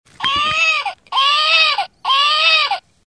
Плач куклы в темноте